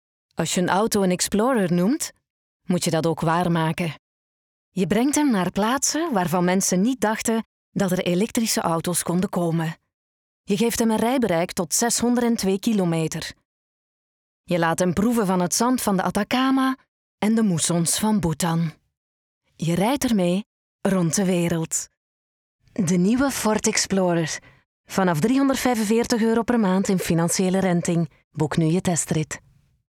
Commercial, Natural, Playful, Reliable, Warm
Corporate